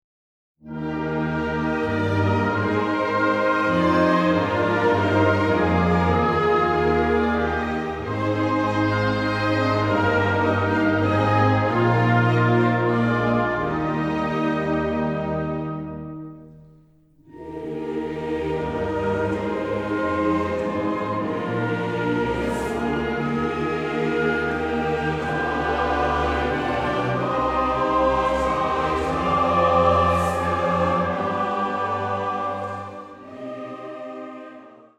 gemischter Chor, Orchester